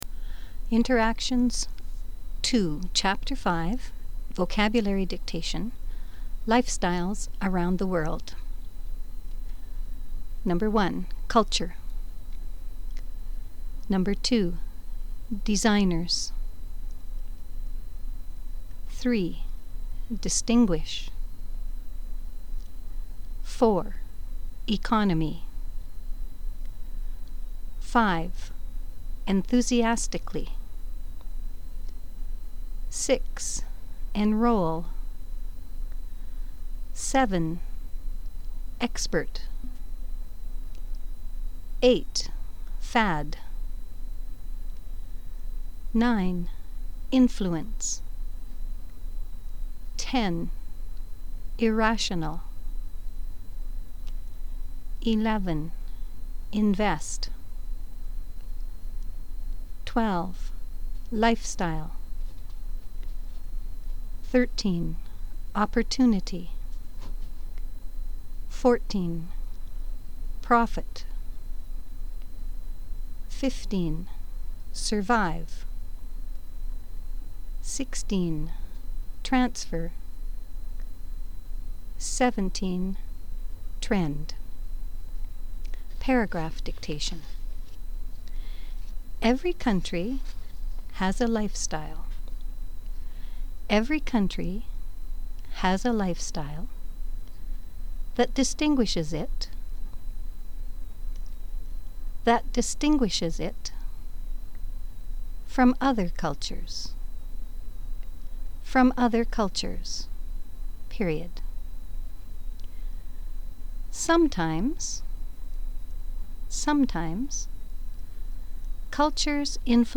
Dictations
First you will hear a list of words.  Then you will hear a paragraph dictation.